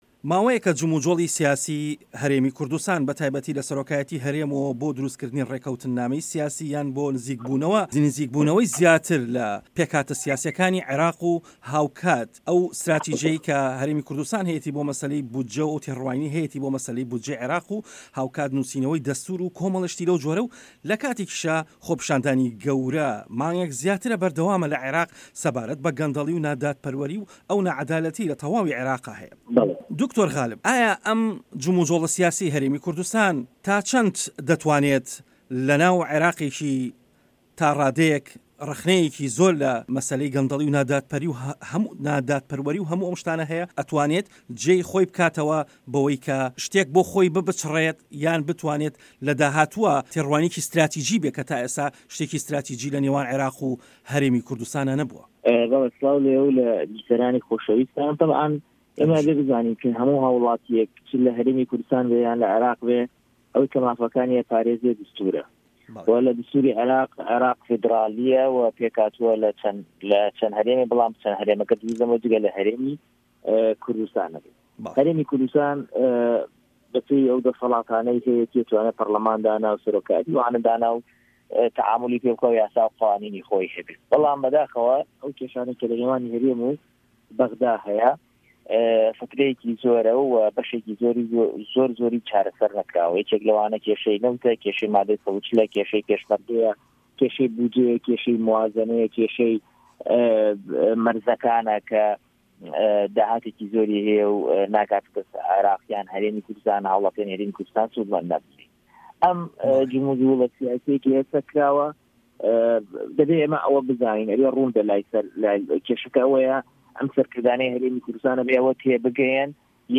وتووێژ لەگەڵ غالب محەمەد